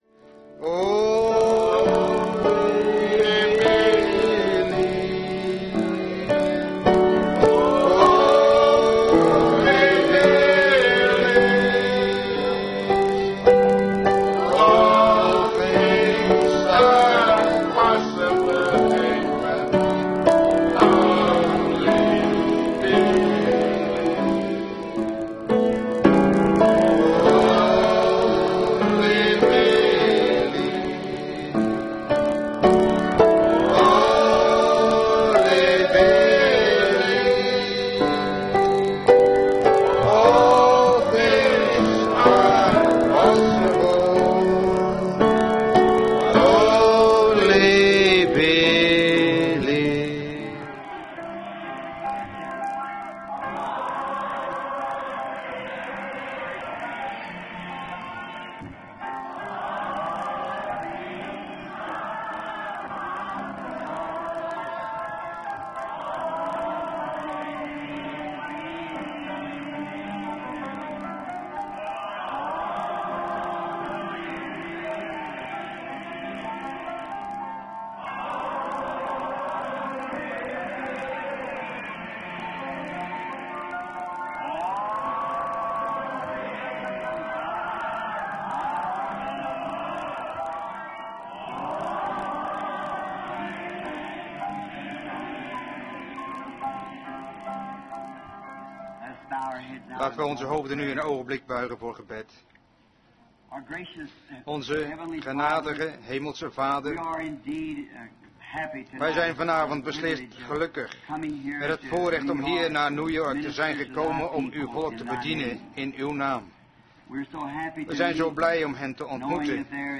Prediking
Locatie Marc ballroom New York , NY